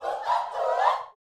SHOUTS18.wav